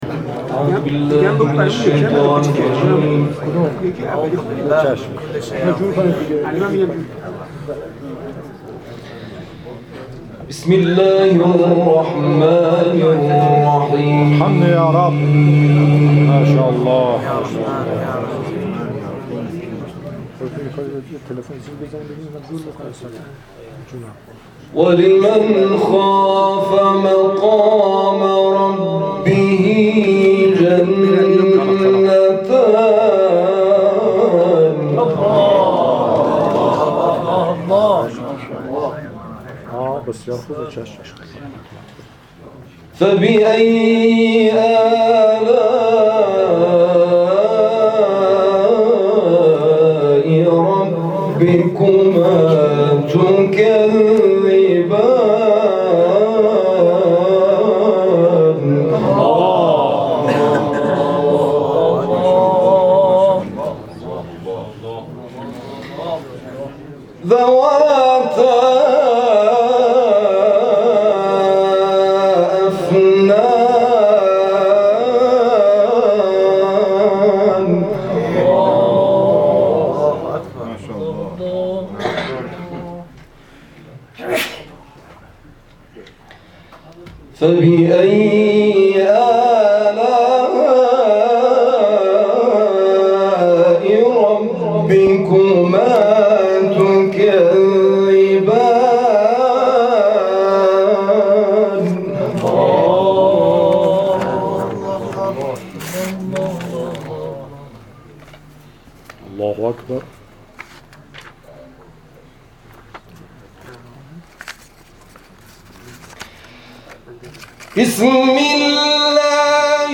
تلاوت‌های کوتاه